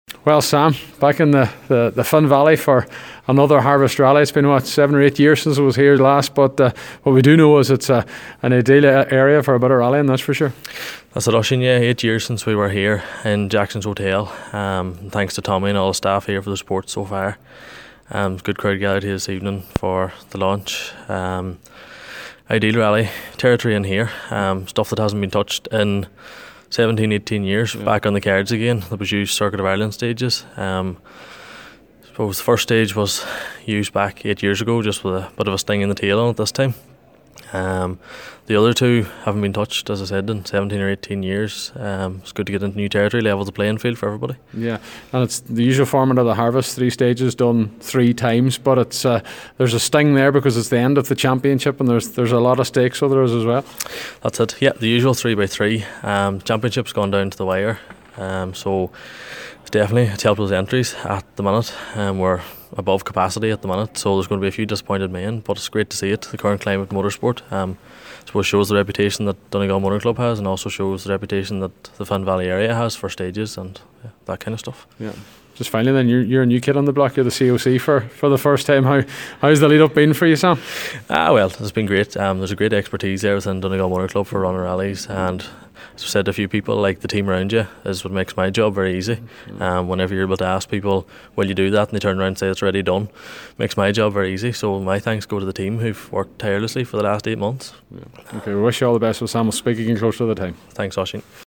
at the rally launch…